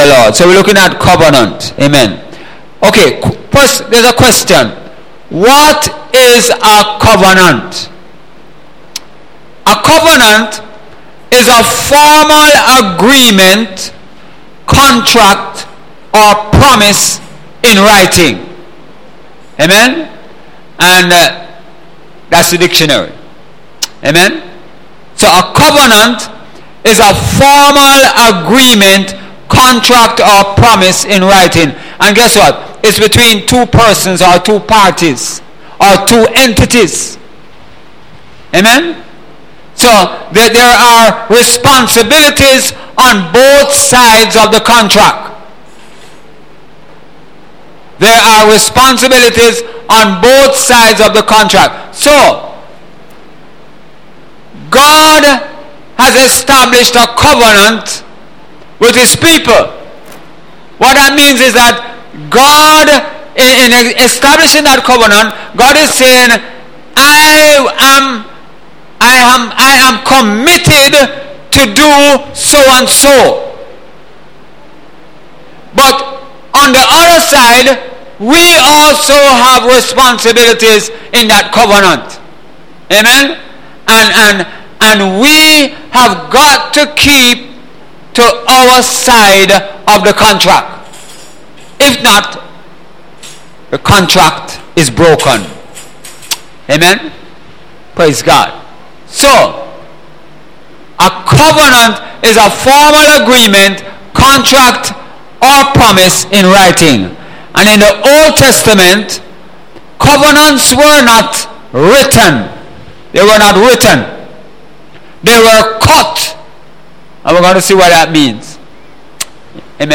Sunday Sermon – The Covenant of God – Sun.